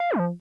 sfx_shieldDown.ogg